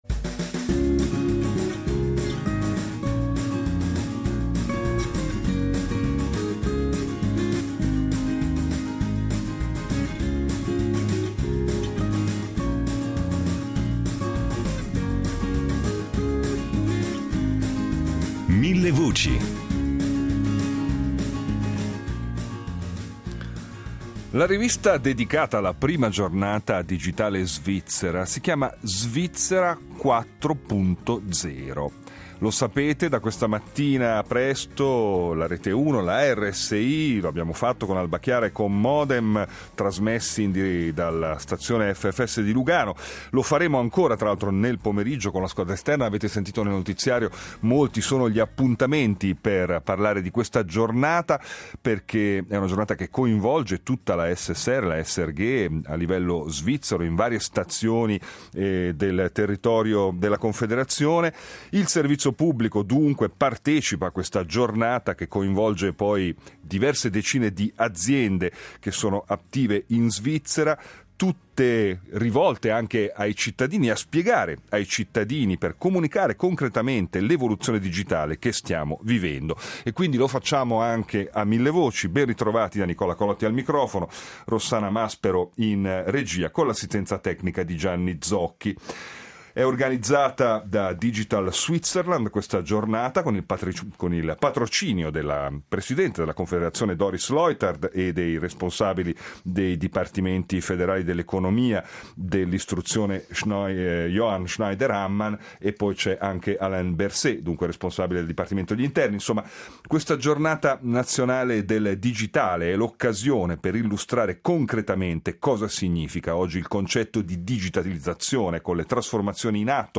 La RSI sarà in diretta In diretta dalla Stazione FFS di Lugano con Rete Uno, Rete Due, Rete Tre, LA1, la redazione del news desk. Anche Millevoci dedica la trasmissione di oggi al tema della rivoluzione digitale, con particolare riferimento alle nuove tendenze nel campo della formazione, della ricerca e dell’applicazione delle tecnologie digitali ad ambiti che toccano direttamente la vita dei cittadini come la salute.